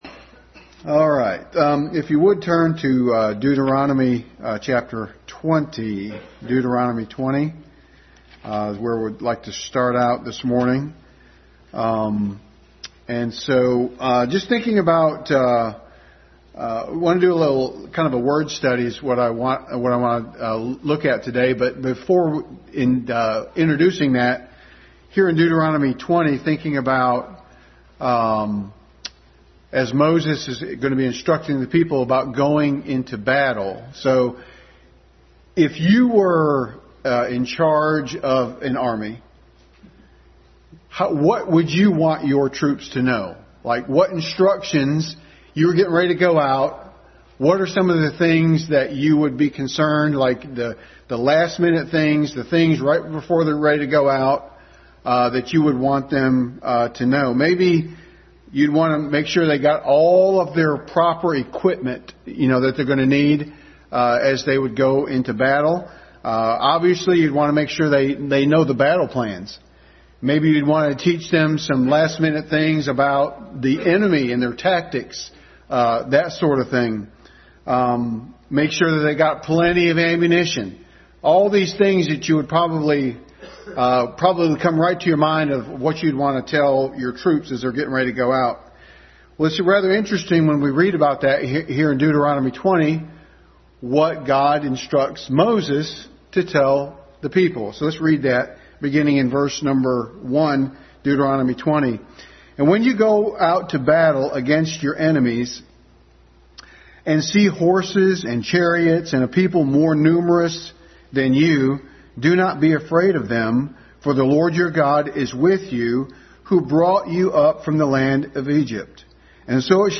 Adult Sunday School.